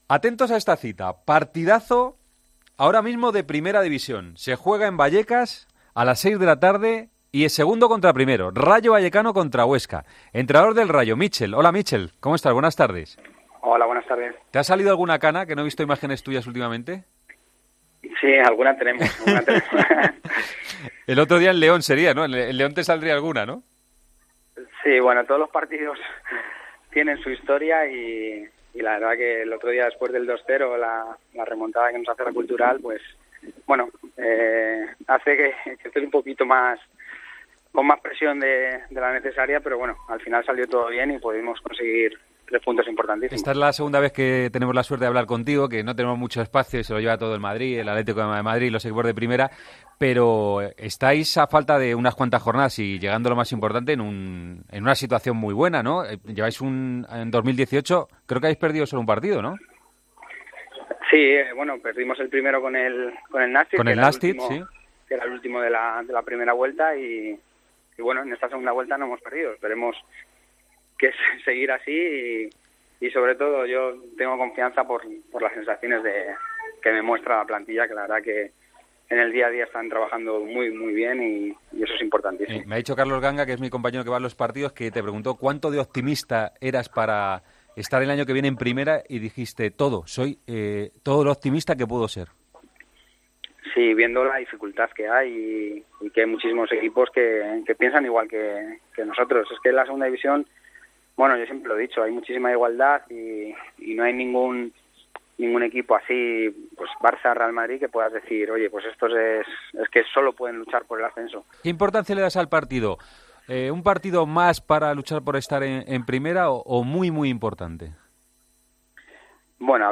Hablamos con el entrenador del Rayo Vallecano en la previa del encuentro entre el primero y el segundo clasificado de la Segunda División.